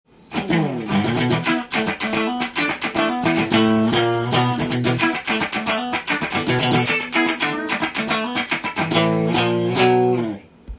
試しに弾いてみました
このギターは音が良いです！カラカラしていて思わずカッティングをしたくなるギターです！